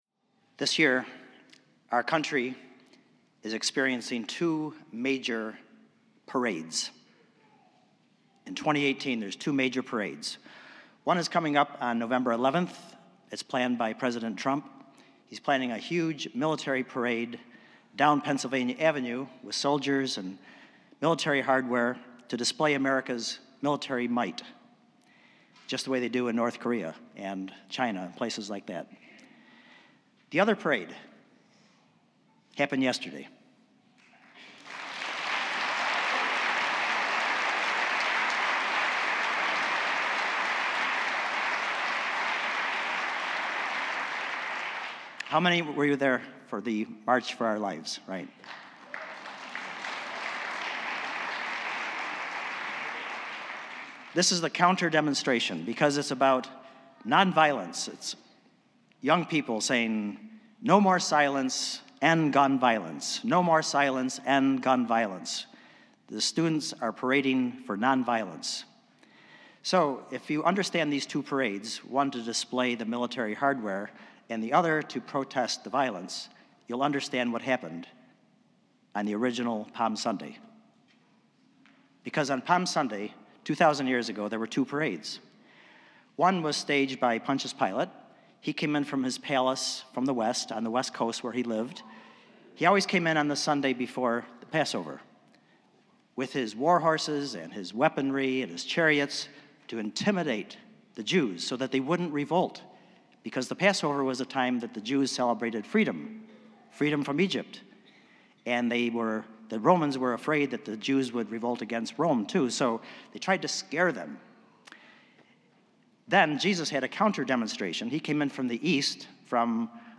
It is Palm Sunday at Spiritus Christi Mass in Rochester, NY.
The Spirit Singers perform the Passion.